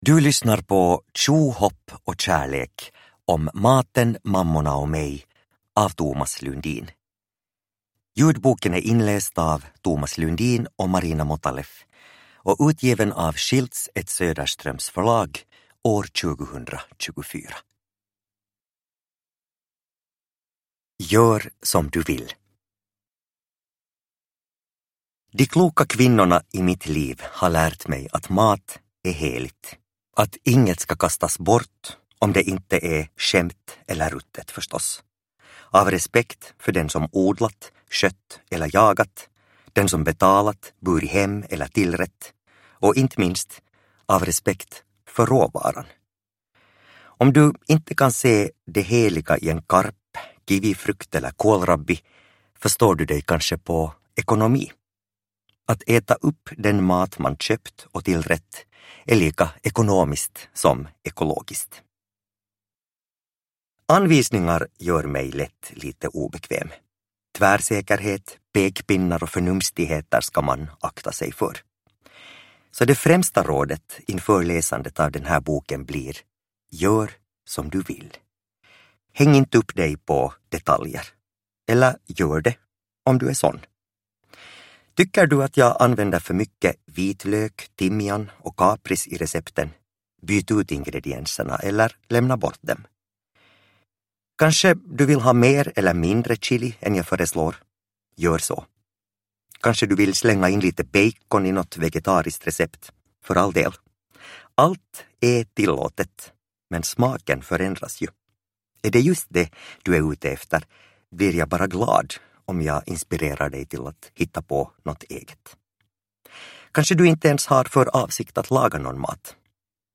Tjo, hopp och kärlek – Ljudbok